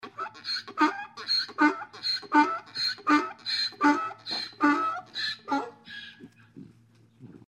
Klingelton Esel Statistiken 142 85 Dateityp MP3